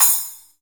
Crashes & Cymbals
CYMBAL_3003.WAV